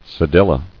[ce·dil·la]